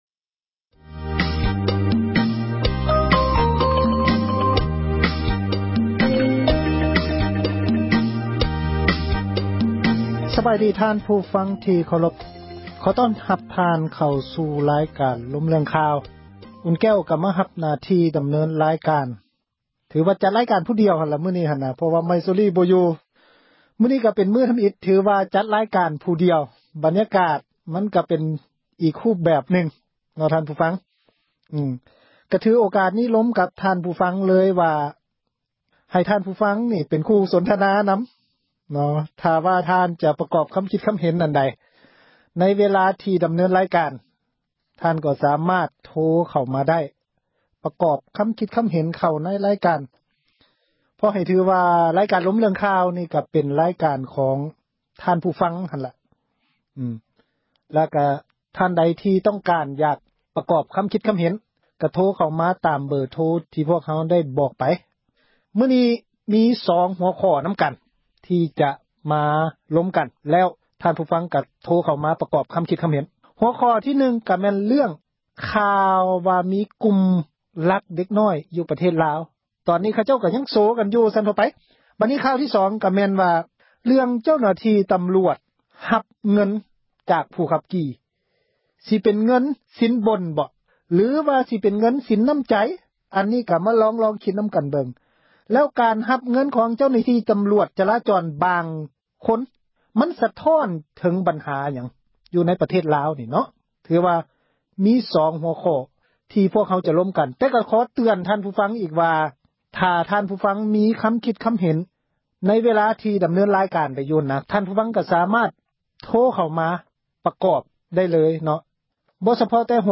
ມື້ນີ້ ເປັນ ເທື່ອ ທຳອິດ ທີ່ໄດ້ ດຳເນີນ ຣາຍການ ຄົນດຽວ, ບັນຍາກາດ ກໍຊິເປັນ ອີກຮູບນຶ່ງ. ໃນ ມື້ນີ້ ມີສອງ ຫົວຂໍ້ ນຳກັນຄື: 1- ແມ່ນ ຂ່າວເຣື້ອງ ແກັງ ລັກເດັກນ້ອຍ ຢູ່ລາວ. 2- ເຣື້ອງ ເຈົ້າໜ້າທີ່ ຈະລາຈອນ ຮັບເງິນ ຈາກ ຜູ້ຂັບຂີ່.